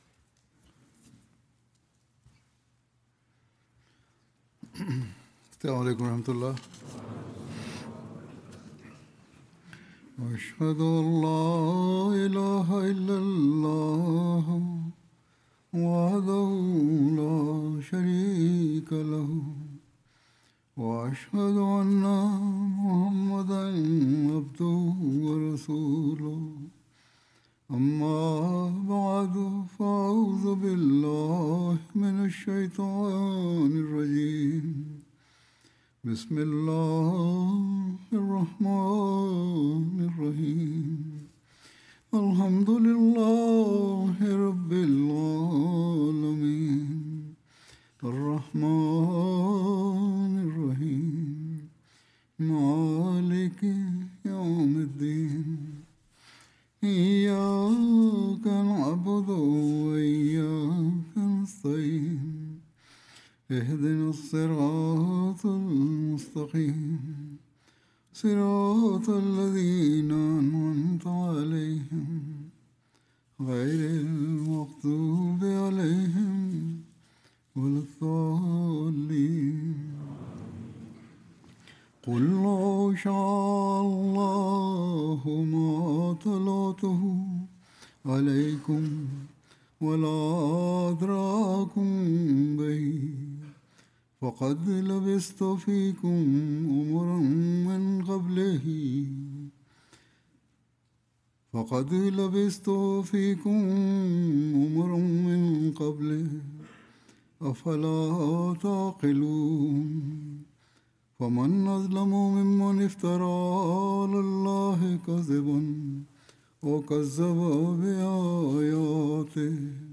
17 April 2026 The Prophet (sa): Al-Sadiq wa Al-Amin: The Truthful and The Trustworthy Swahili Friday Sermon by Head of Ahmadiyya Muslim Community 45 min About Swahili translation of Friday Sermon delivered by Khalifa-tul-Masih on April 17th, 2026 (audio)